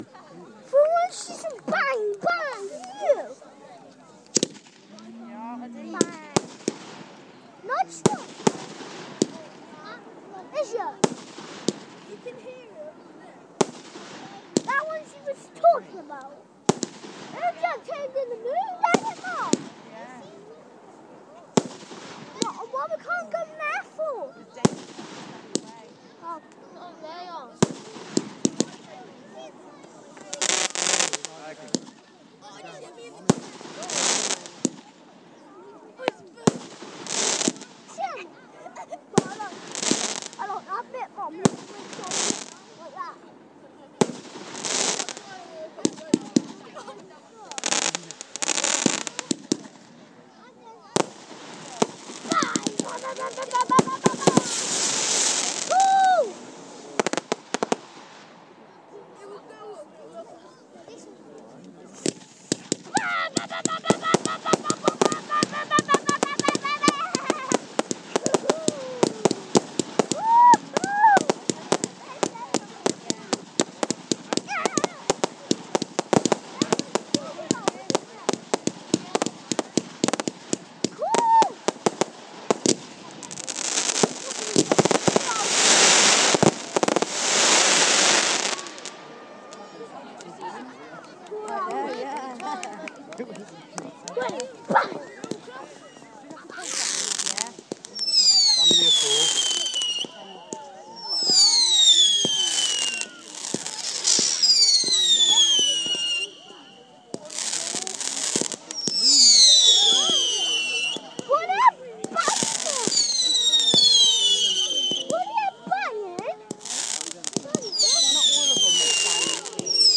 Amazing Pershore Fireworks part 2